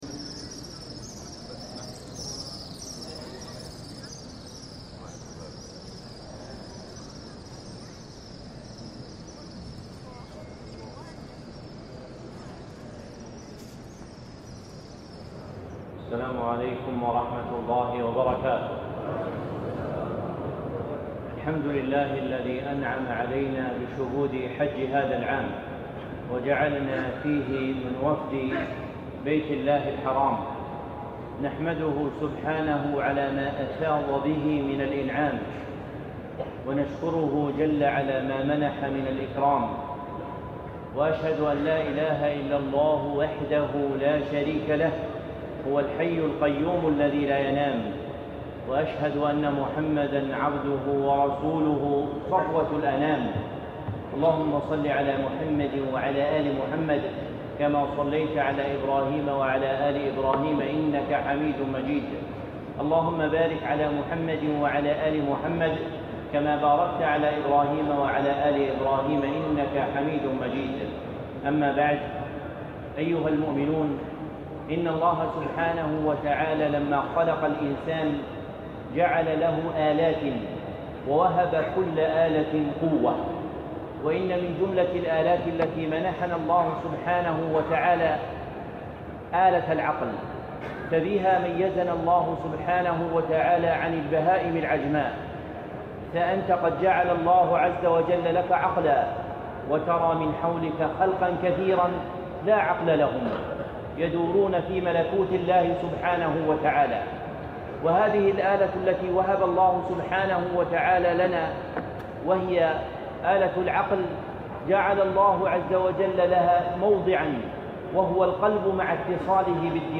حال المسلم بعد الحج محاضرة عام 1440